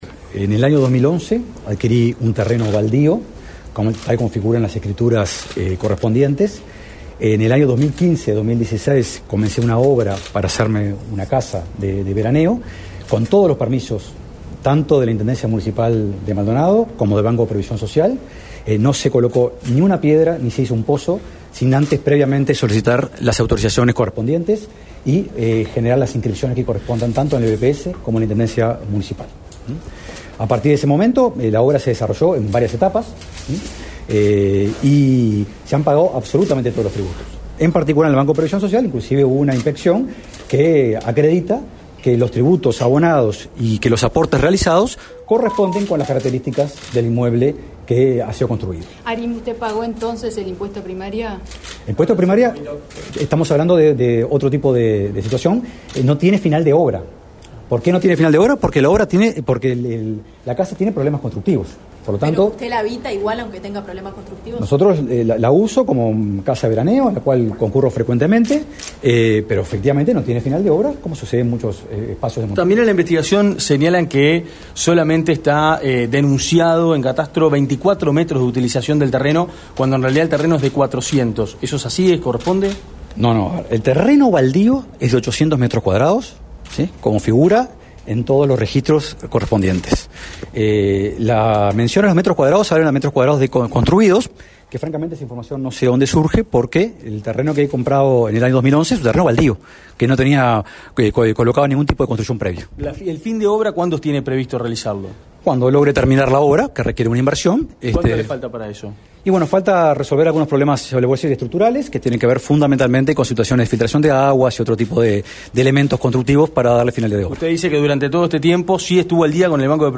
El director de la Oficina de Planeamiento y Presupuesto, Rodrigo Arim, dijo en rueda de prensa este martes en Torre Ejecutiva, que tiene la “enorme tranquilidad de no haber incurrido en ningún elemento que implique en ocultar” su vivienda ni ninguna obra, luego de que el programa La Pecera de Azul FM divulgara un informe destacando ciertas irregularidades en una casa construída en el balneario Solis.
Rueda-de-Prensa-Arim.mp3